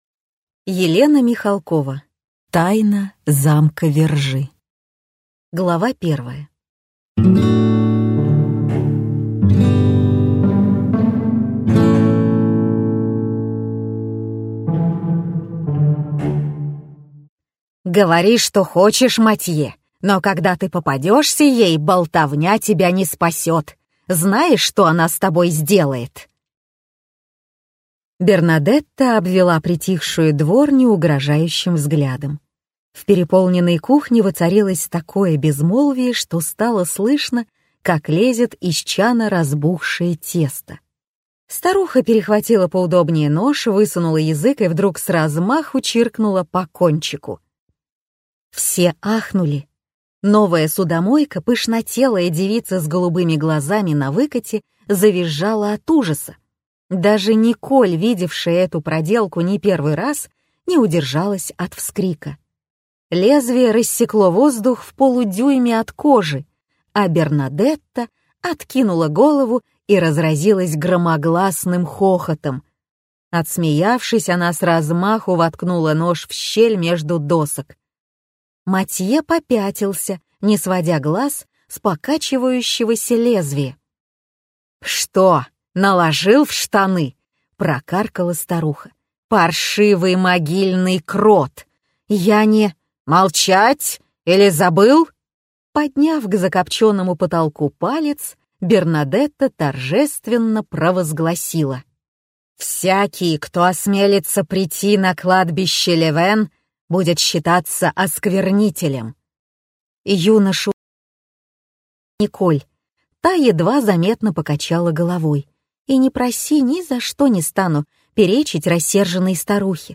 Прослушать фрагмент аудиокниги Тайна замка Вержи Елена Михалкова Произведений: 23 Скачать бесплатно книгу Скачать в MP3 Вы скачиваете фрагмент книги, предоставленный издательством